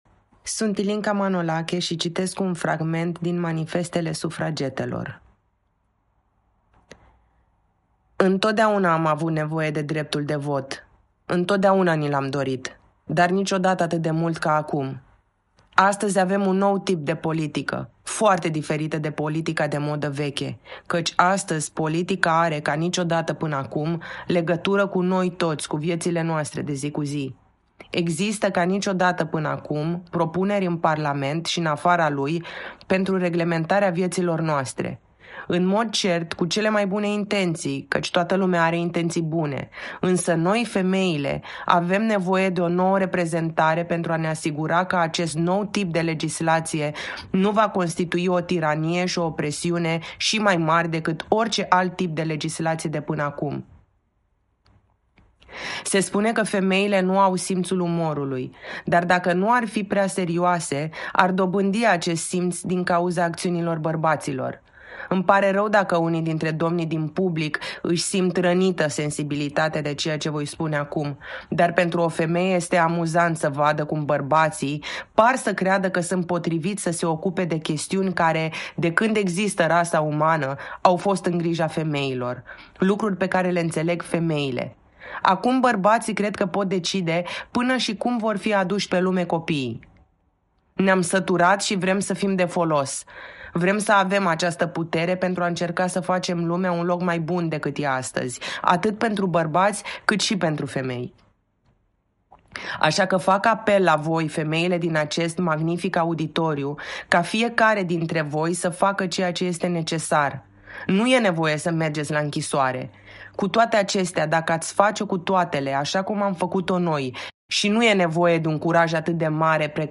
Prin Linia Autoarelor – un cor de voci care invită la o ascultare activă, timp de două-trei minute, fragmente ale unor opere scrise de femei, citite de femei.
Fragment din Manifestele Sufragetelor, citit de actrița